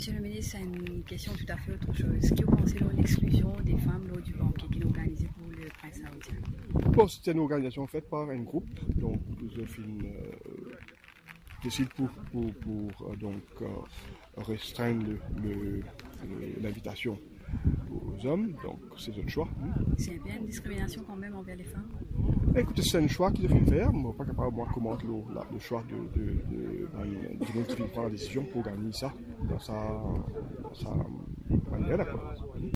C’est la question que nous avons posée à Mahen Seeruttun.
C’était ce jeudi 5 juillet, à Vacoas, à l’issue du lancement d’un projet de restauration de la biodiversité le long de ka rivière Takamaka.